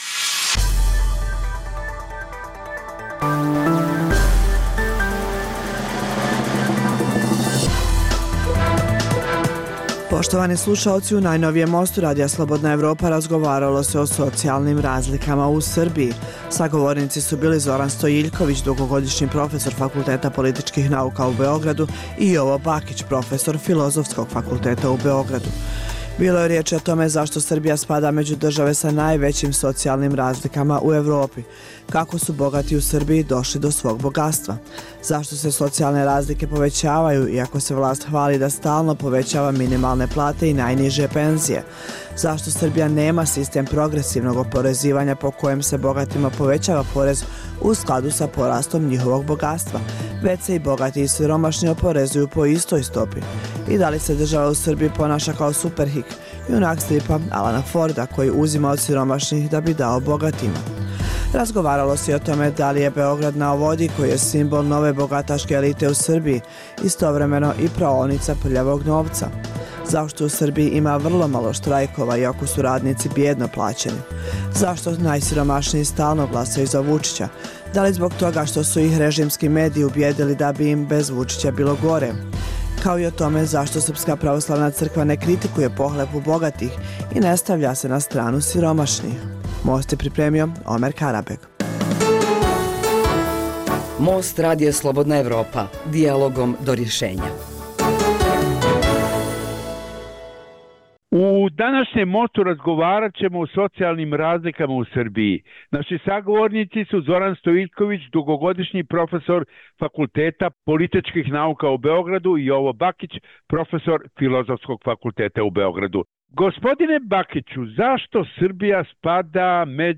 U najnovijem Mostu Radija Slobodna Evropa razgovaralo se o socijalnim razlikama u Srbiji.